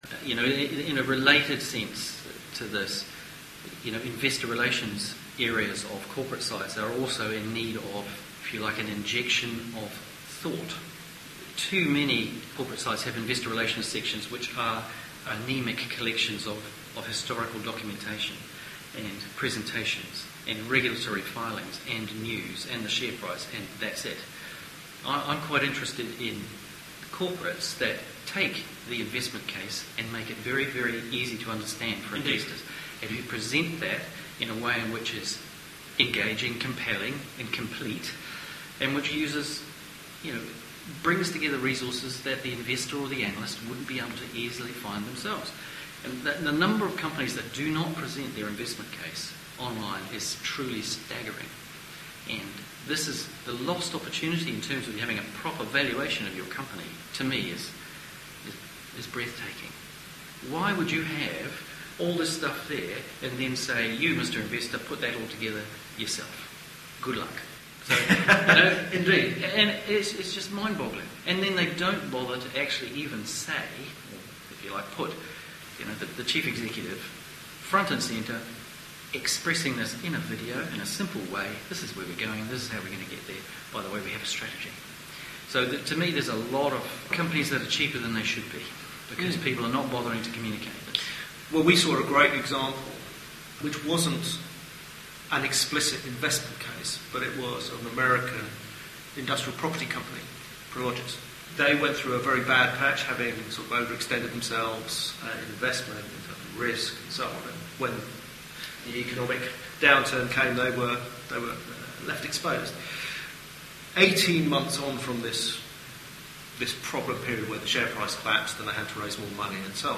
The Group interview: part 6